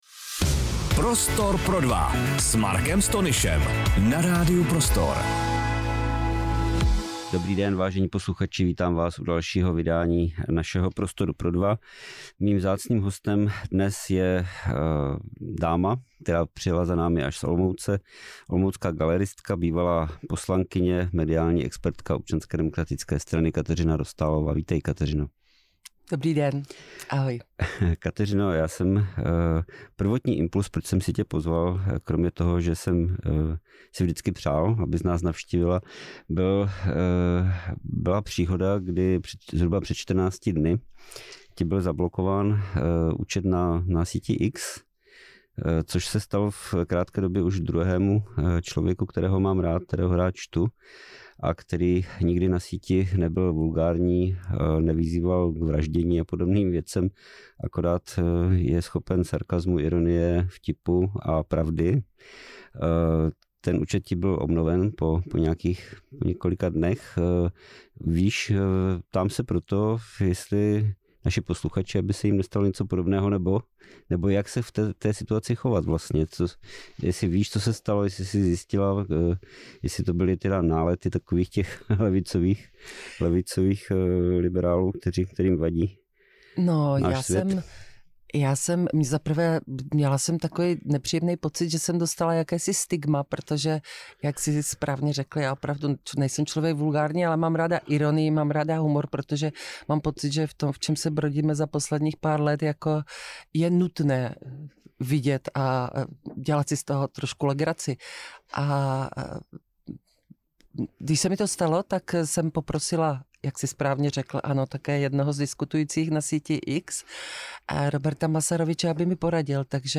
Rozhovor s Kateřinou Dostálovou